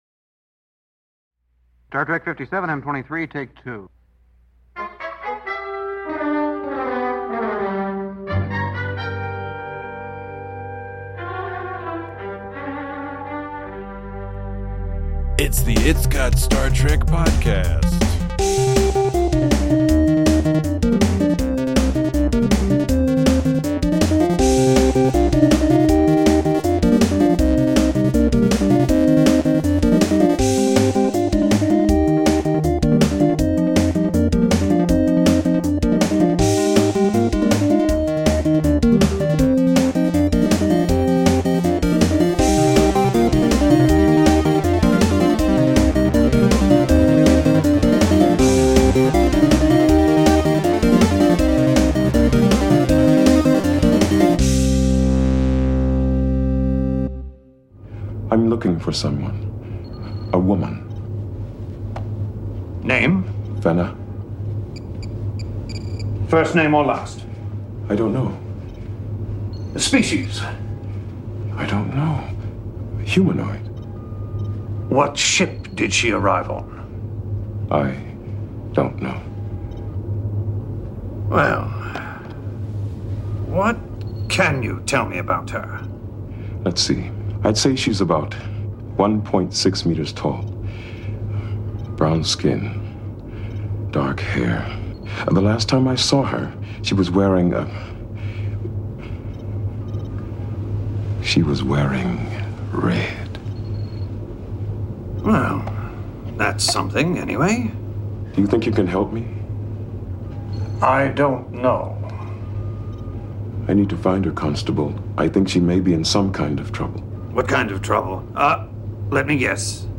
Strap in, as this is one of those classic "high energy" IGSTs...enjoy!